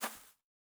Bare Step Grass Medium B.wav